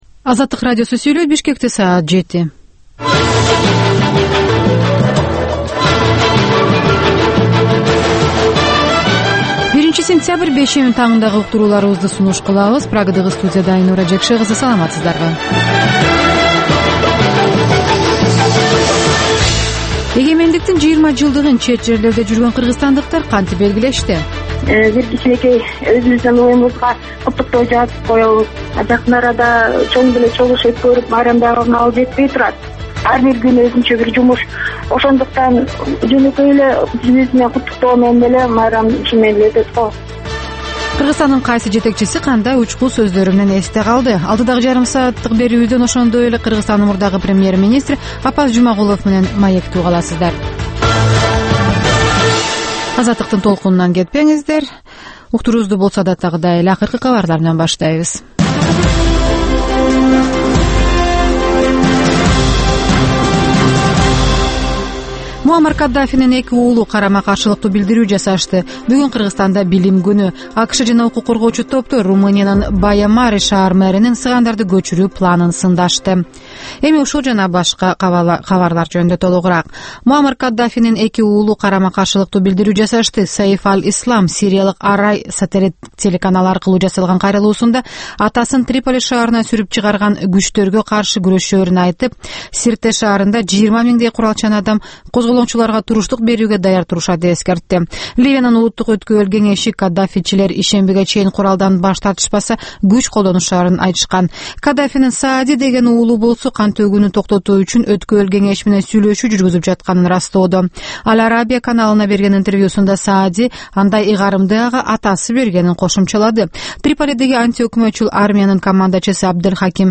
Таңкы 7деги кабарлар